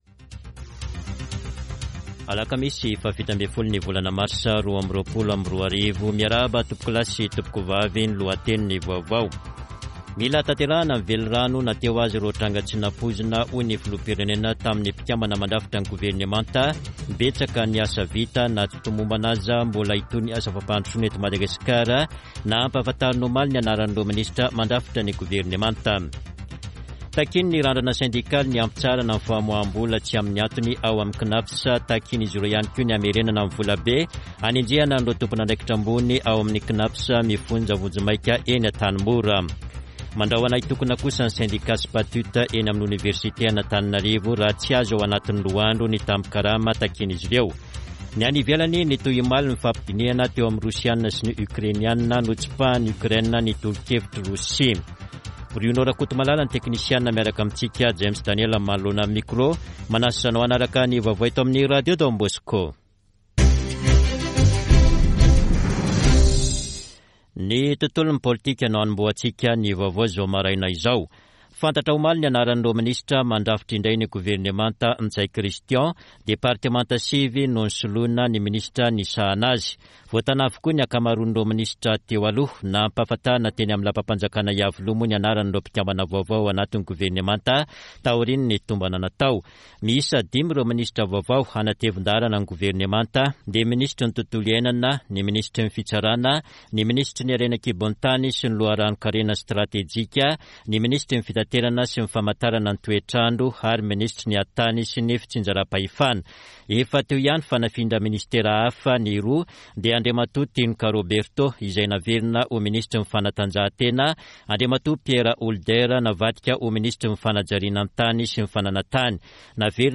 [Vaovao maraina] Alakamisy 17 marsa 2022